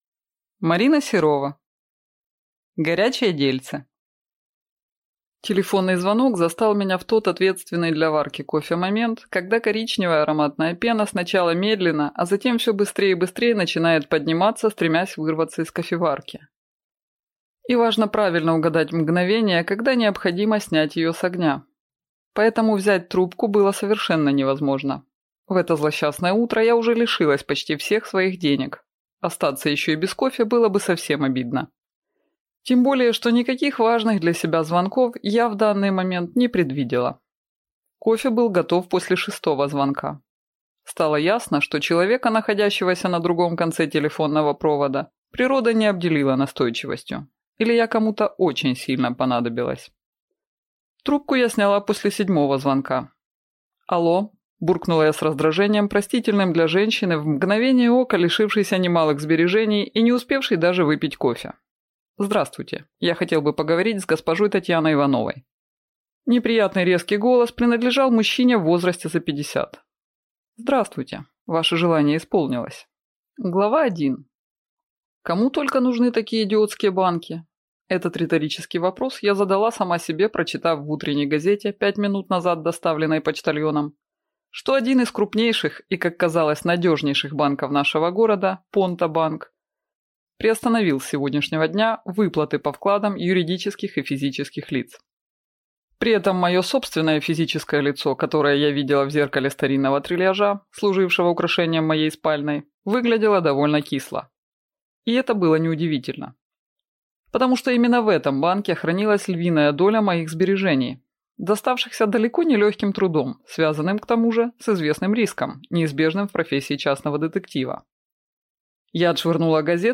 Аудиокнига Горячее дельце | Библиотека аудиокниг